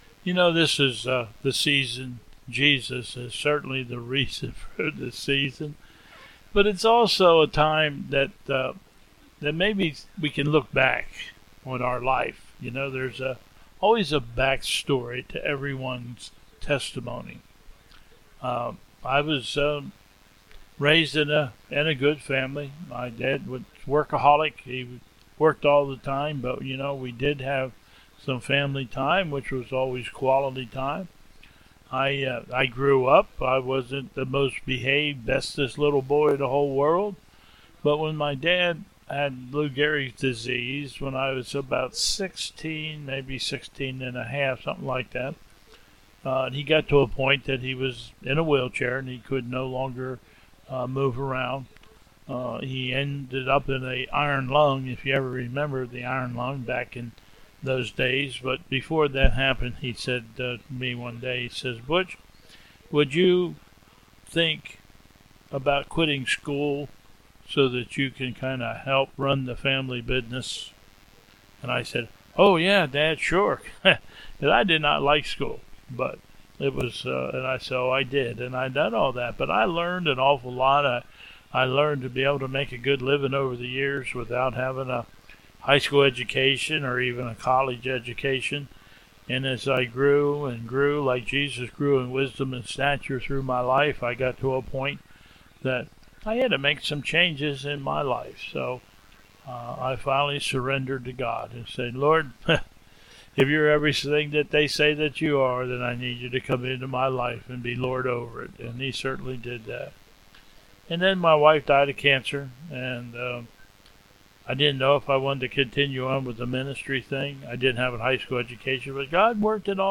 Slides - Pastor's Thoughts, Prayers and Lord's Prayer .